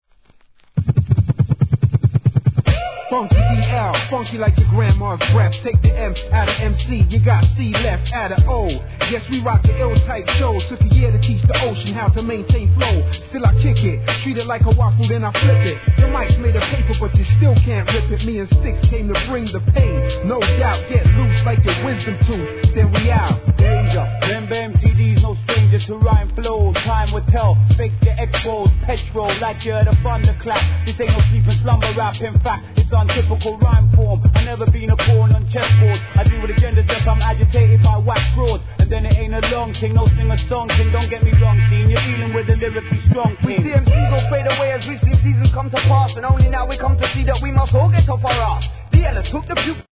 HIP HOP/R&B
オールドスクール調のドラムブレイクに、個性派 揃いのロンドン発のMCリレー!